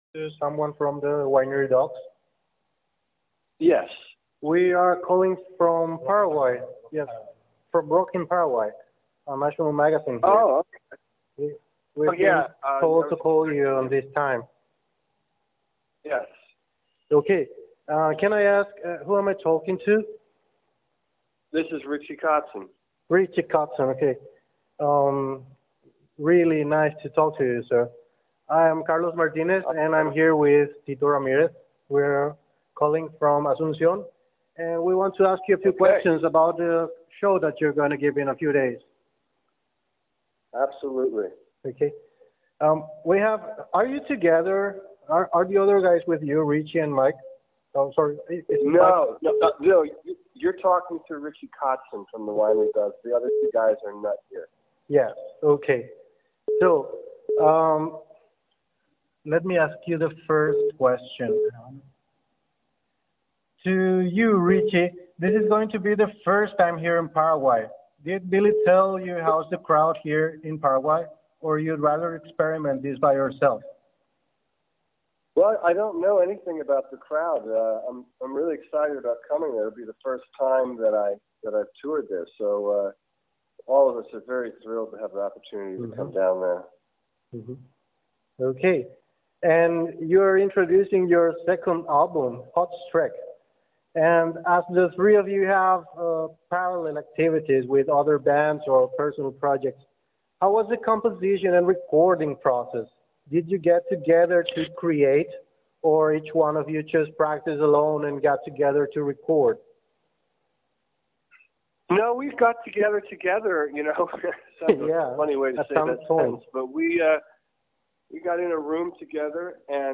Entrevista exclusiva a Richie Kotzen de The Winery Dogs! – Rock En Paraguay
Tuvimos el honor de comunicarnos directamente con Richie Kotzen, guitarrista y vocalista de The Winery Dogs, grupo que estará presentandose en Asunción el próximo 24 de Mayo en el Teatro de Hotel Guaraní.
Entrevista-a-Richie-Kotzen-mp3.mp3